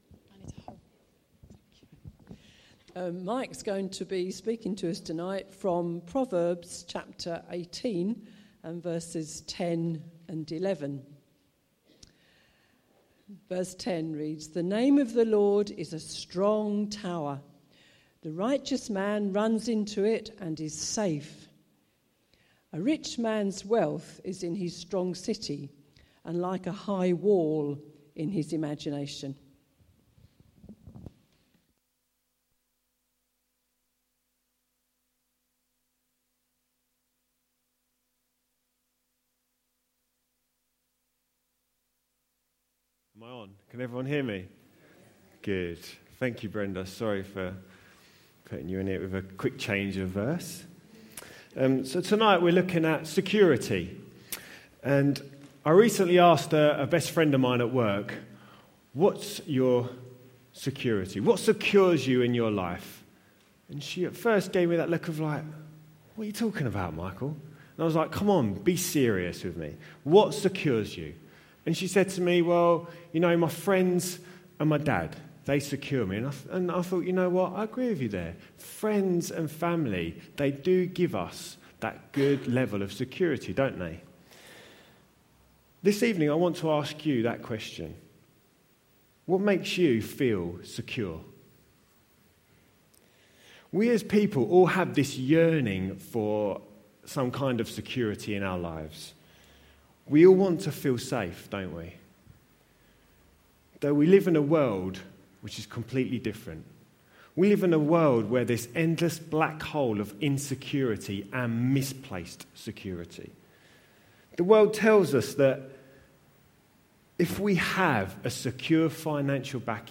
A message from the series "Security."